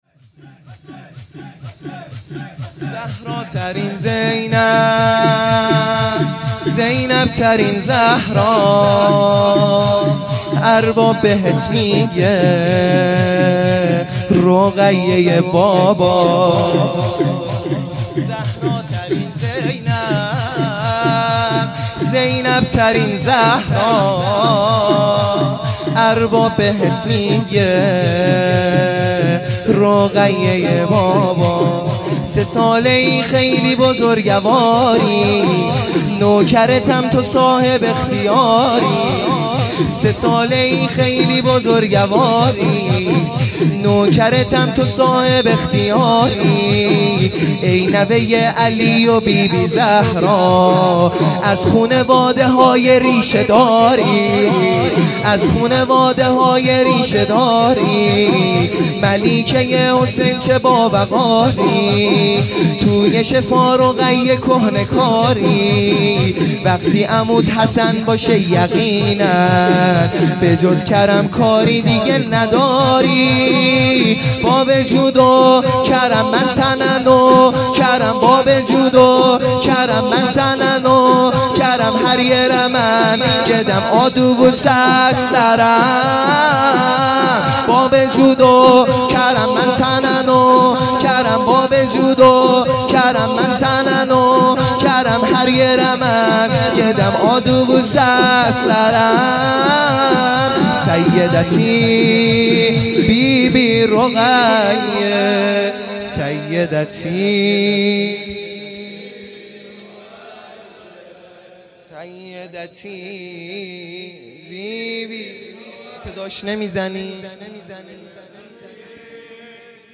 شور | سه ساله ای خیلی بزرگواری
جلسه هفتگی